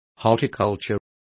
Complete with pronunciation of the translation of horticulture.